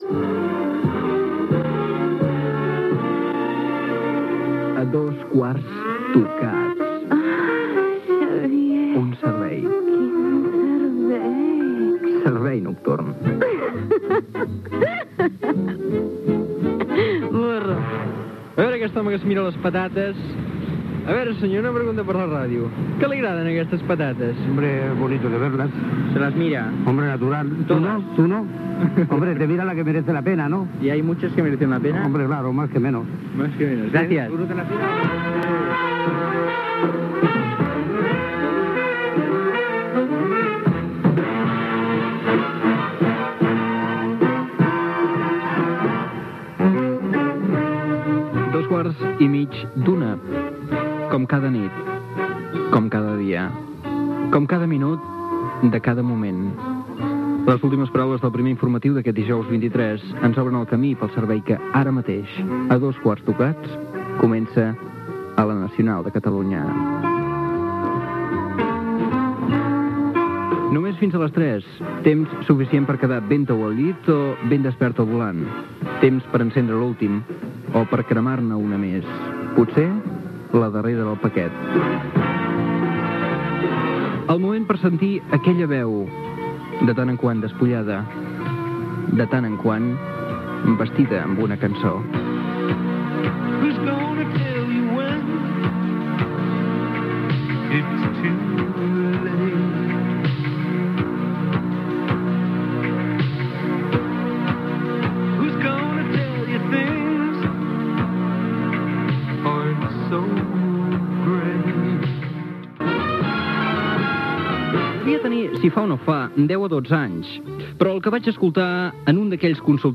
Careta del programa, diàleg amb un venedor de patates, hora, presentació, tema musical, record de la carta d'un consultori radiofònic i explicació d'una història personal d'un embaràs no volgut
Entreteniment
FM